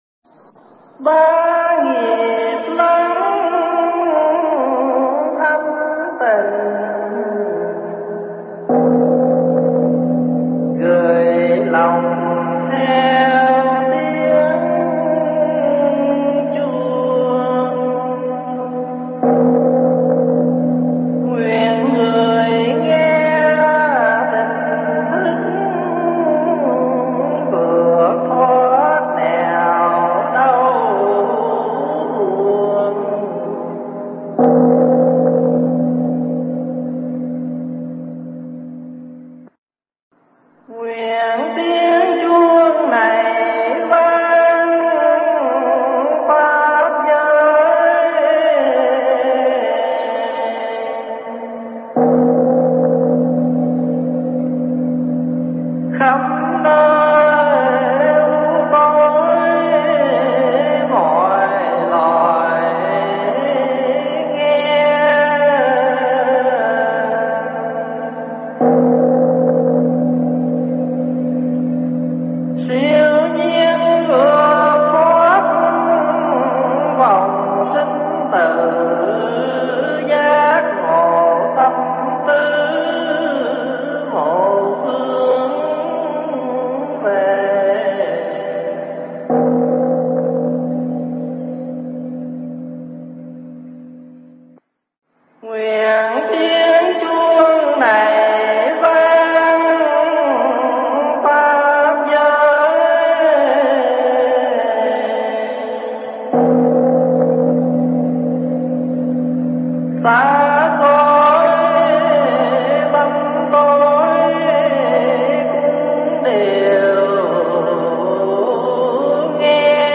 thuyết giảng tại Tu Viện Tây Thiên, Canada